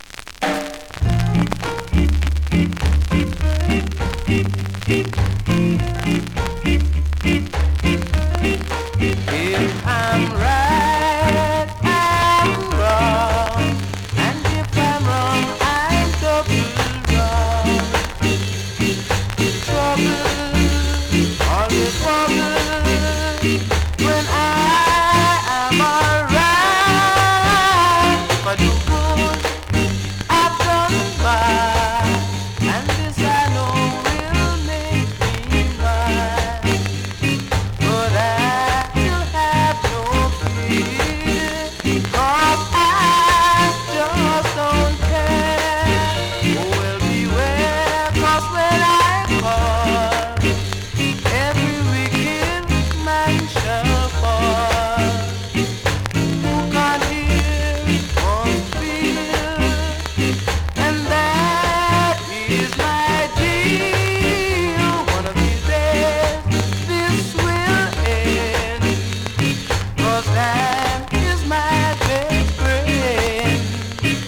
ROCKSTEADY
スリキズ、ノイズそこそこあります。